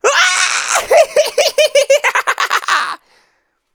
13467-clown-jeer-laughter.wav